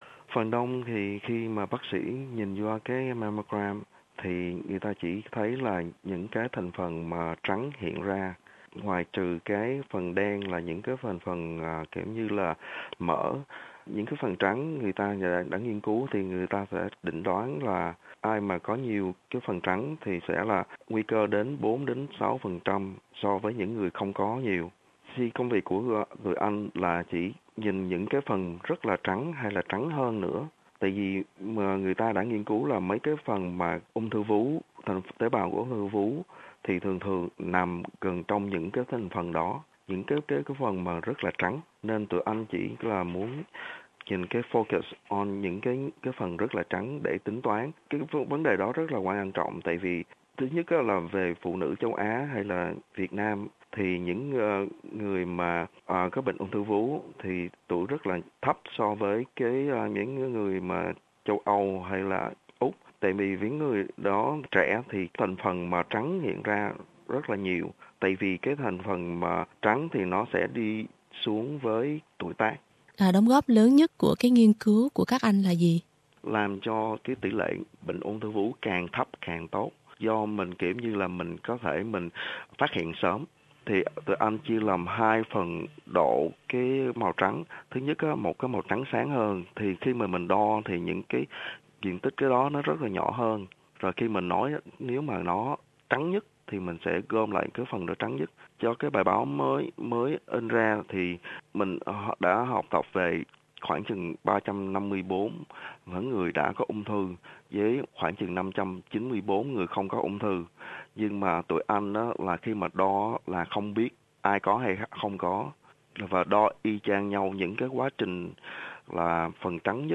Trả lời phỏng vấn SBS Việt ngữ